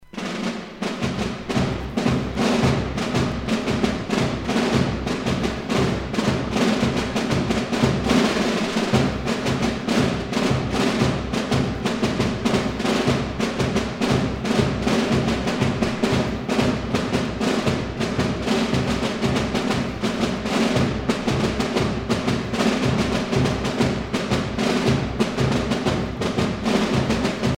gestuel : à marcher
circonstance : carnaval, mardi-gras
Pièce musicale éditée